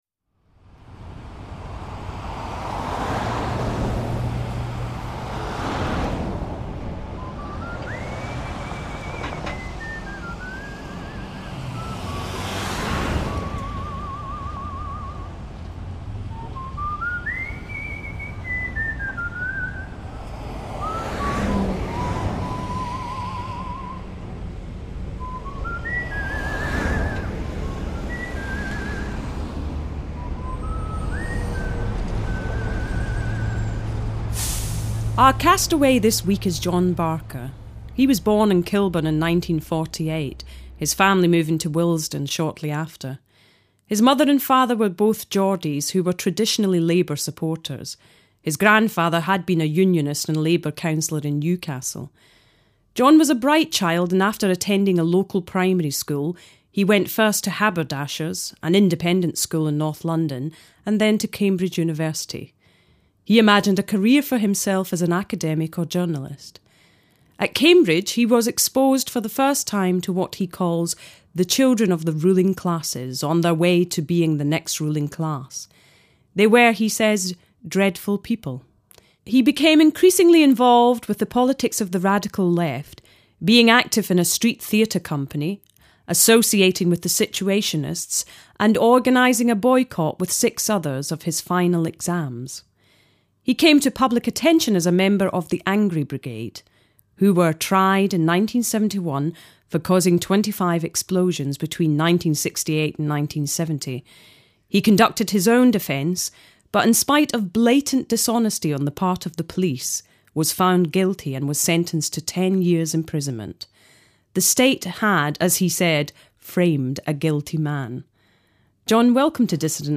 Re-calibrate your DAB, re-align your satellite, and you’ll find a world where Desert Island Dissidents is a popular radio show featuring interviews with ex members of the Angry Brigade (a pocket-sized left wing terrorist group active in the early 70s), where the Arab Spring is brought uncomfortably close to home, and where Sunday evening TV features Time Watch-esque explorations of corners of the Earth’s surface we’d rather remained off-limits and buried for good.